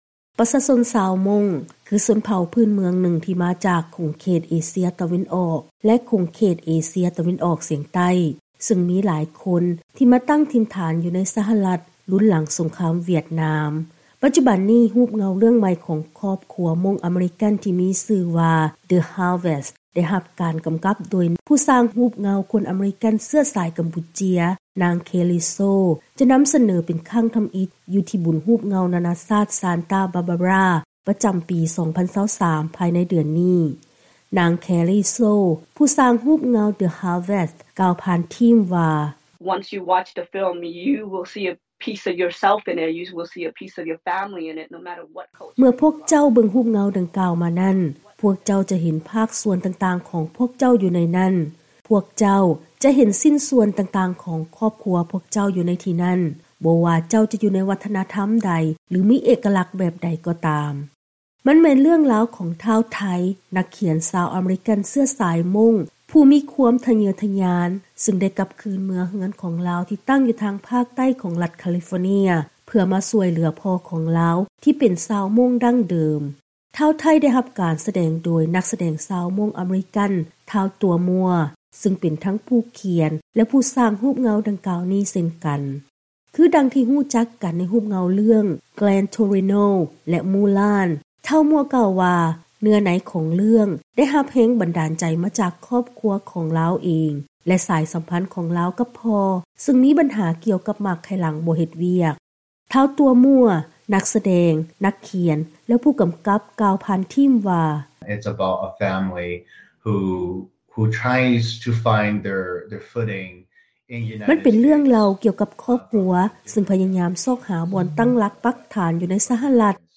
ລາຍງານກ່ຽວກັບ ກຽມສາຍຮູບເງົາຂອງຜູ້ອົບພະນົຍບຊາວມົ້ງຄັ້ງທໍາອິດ ຢູ່ໃນທົ່ວໂລກ.